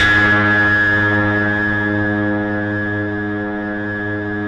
RESMET G#2-R.wav